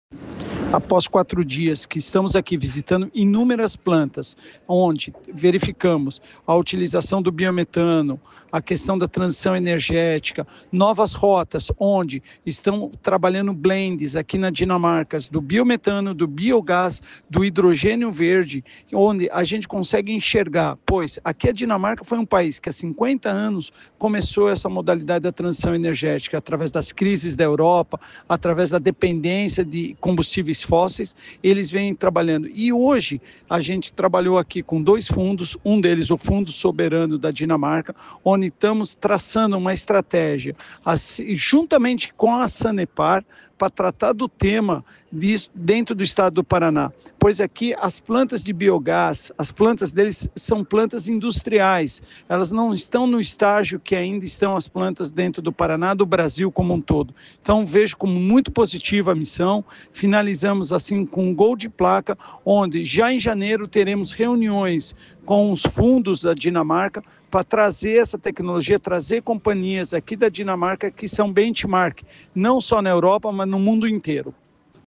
Sonora do diretor-presidente da Invest Paraná, Eduardo Bekin, sobre a missâo da Agência à Dinamarca